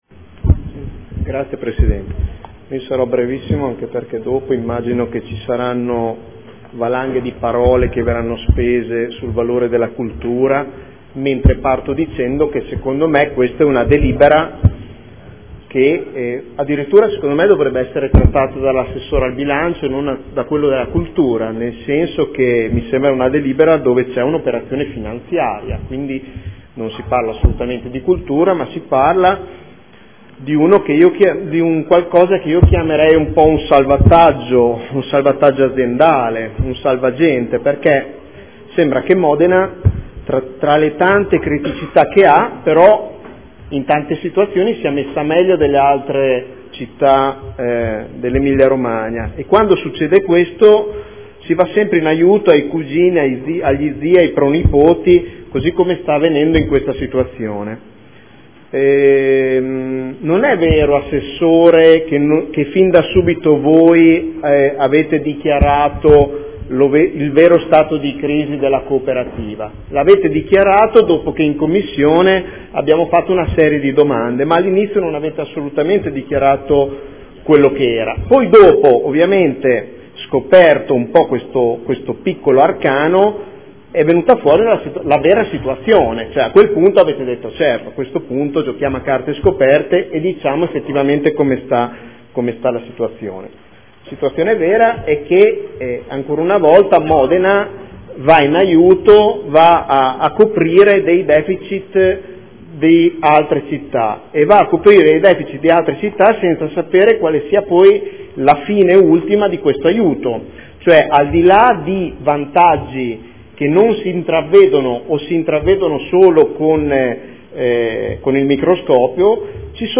Nicola Rossi — Sito Audio Consiglio Comunale